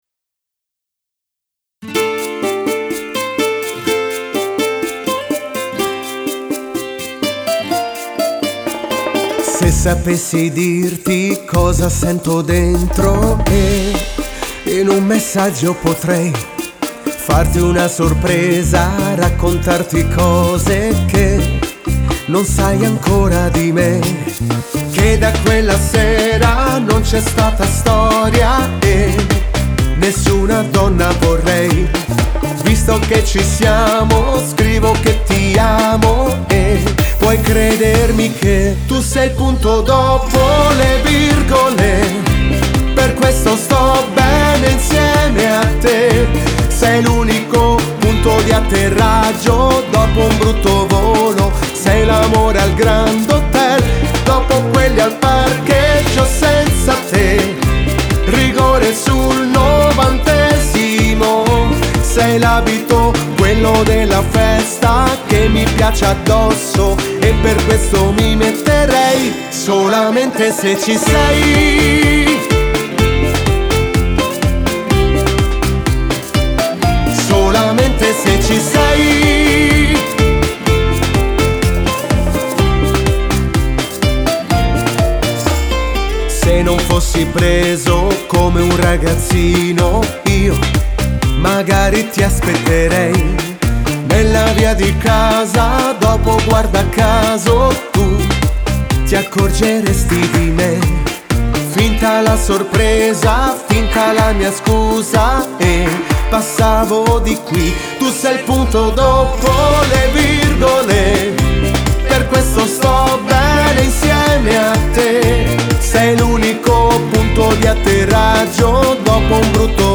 Bachata pop